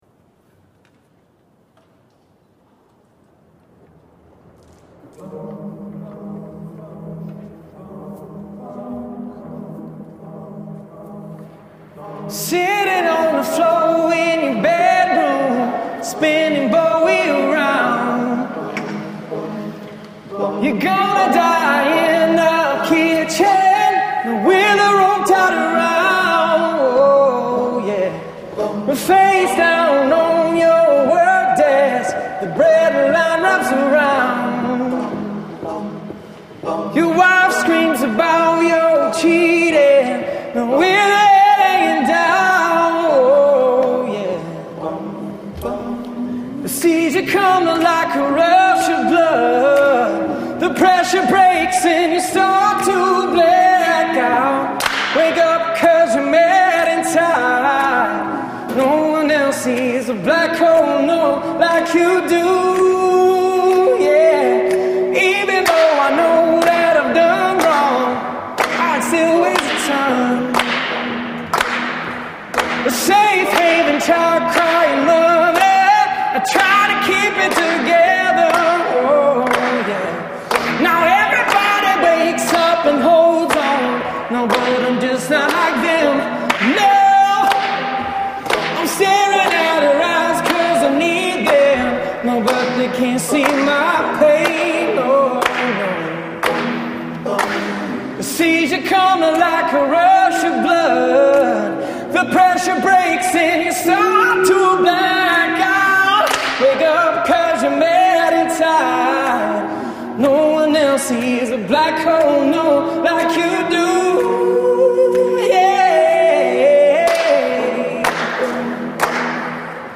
a capella at UW